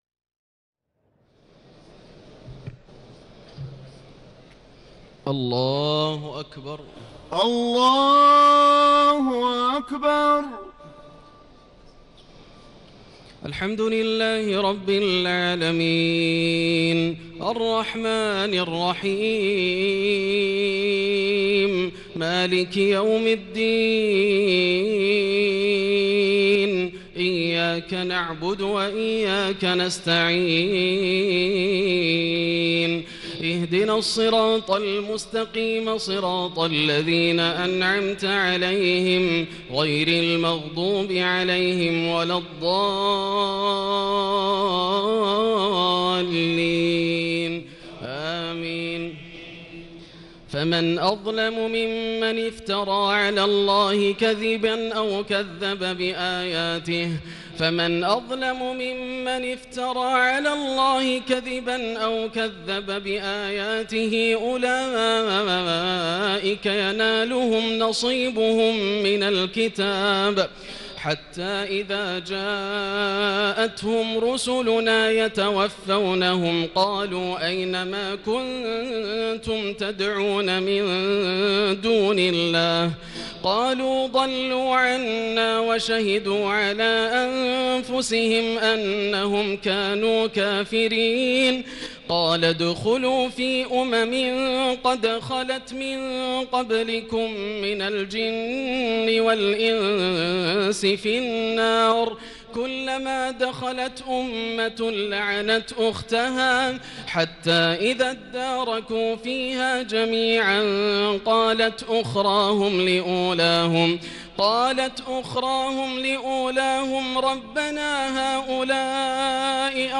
تهجد ليلة 28 رمضان 1439هـ من سورة الأعراف (37-93) Tahajjud 28 st night Ramadan 1439H from Surah Al-A’raf > تراويح الحرم المكي عام 1439 🕋 > التراويح - تلاوات الحرمين